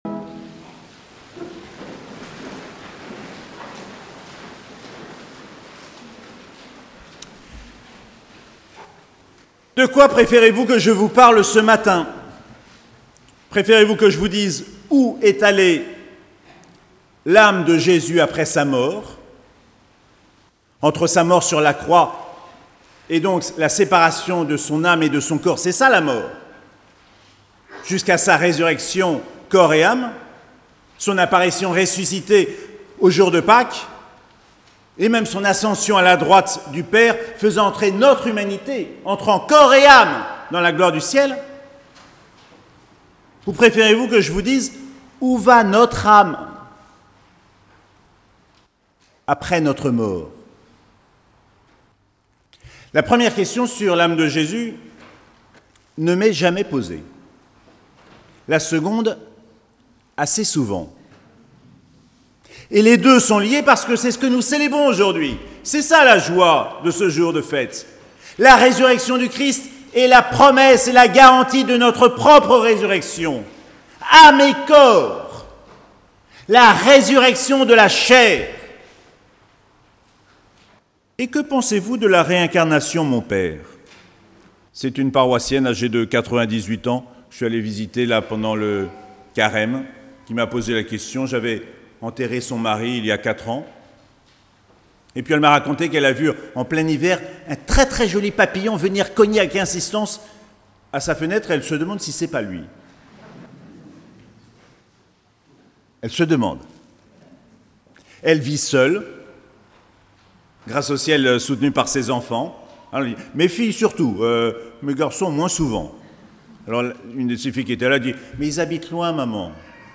Matin de Pâques - dimanche 4 avril 2021